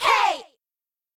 okItsOngirls2.ogg